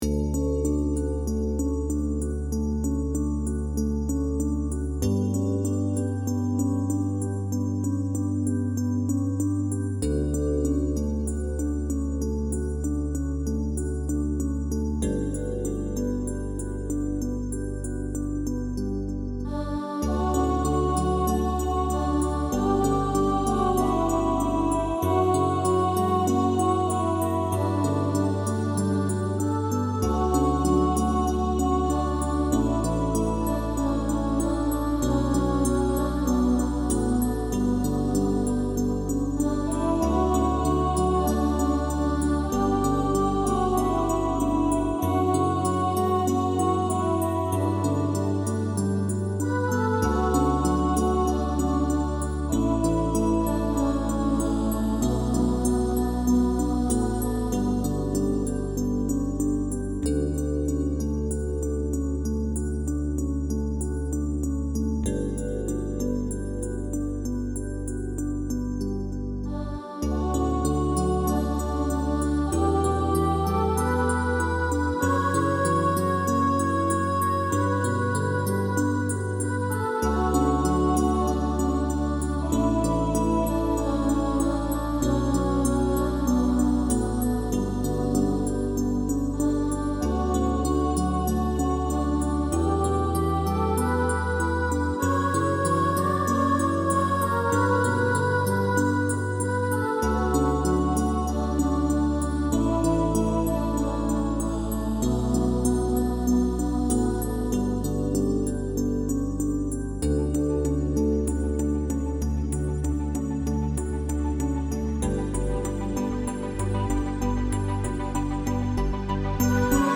lullaby_with_vocals-AQEDgzMLkoUWyBD8.mp3